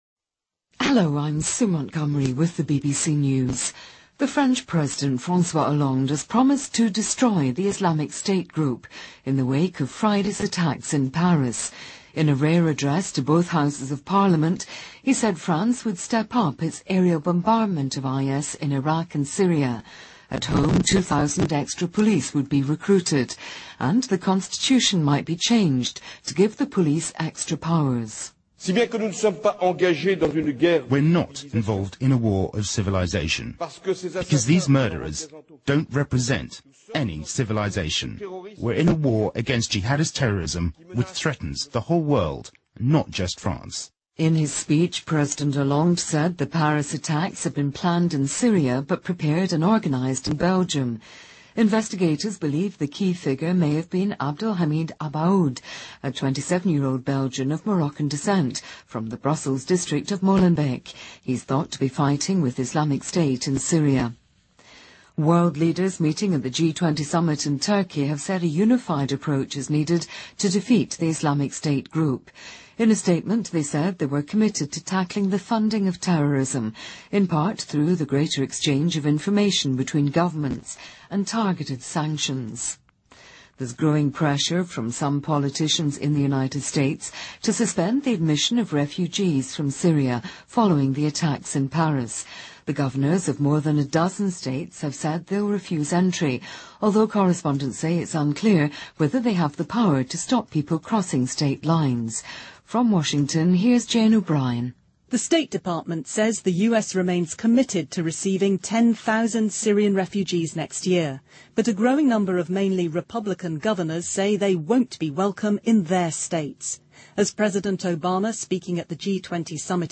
BBC news,法国将尽全力摧毁"伊斯兰国"
日期:2015-11-18来源:BBC新闻听力 编辑:给力英语BBC频道